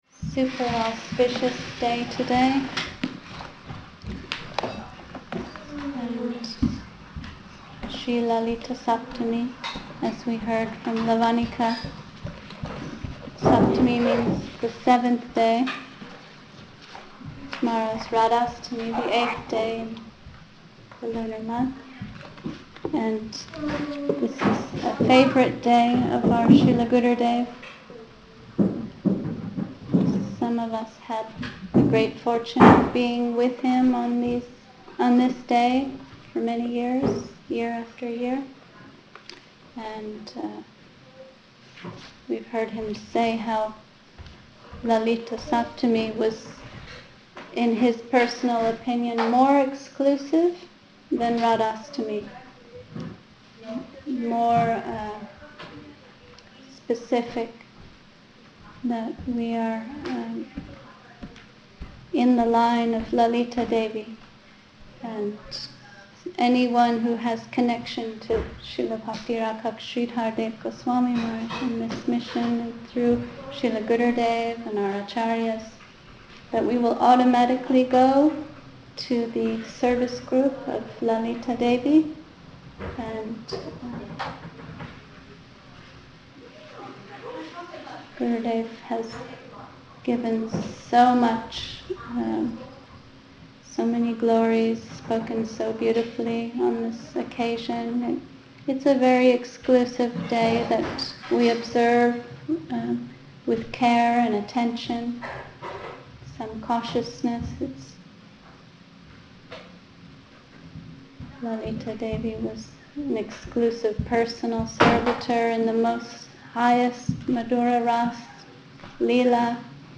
Place: Sri Chaitanya Saraswat Seva Ashram Soquel